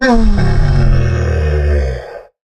assets / minecraft / sounds / mob / camel / sit1.ogg
sit1.ogg